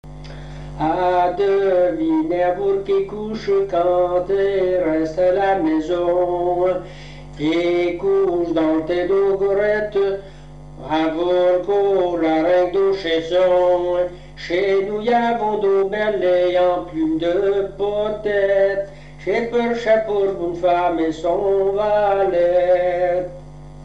Premier couplet inaudible
Genre strophique
répertoire de chansons
Pièce musicale inédite